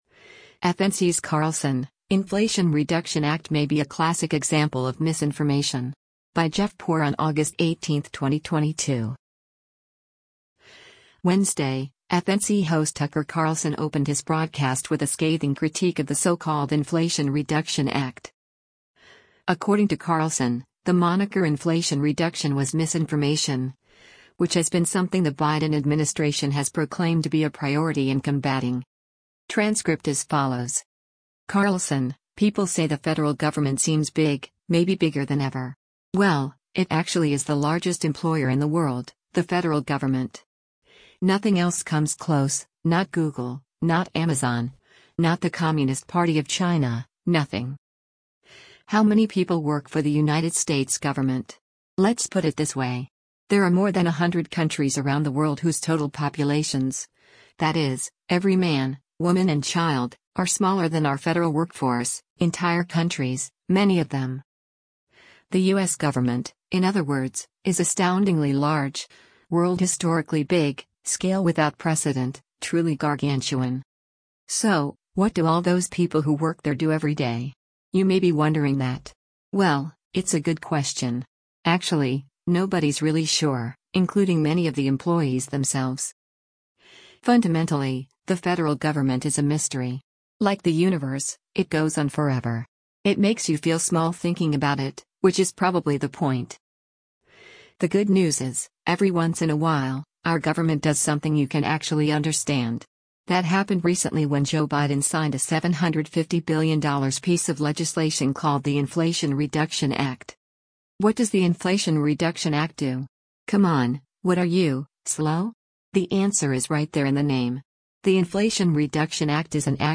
Wednesday, FNC host Tucker Carlson opened his broadcast with a scathing critique of the so-called Inflation Reduction Act.